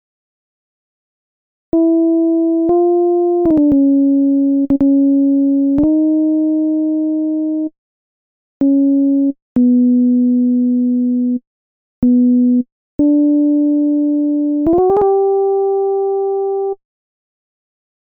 Key written in: C Major
Each recording below is single part only.